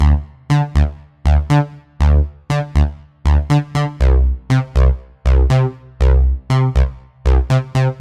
loops basses dance 120 - 2
Téléchargez et écoutez tous les sons et loops de basses style dance music tempo 120bpm enregistrés et disponibles sur les banques de sons gratuites en ligne d'Universal-Soundbank pour tous les musiciens, cinéastes, studios d'enregistrements, DJs, réalisateurs, soundesigners et tous ceux recherchant des sons de qualité professionnelle.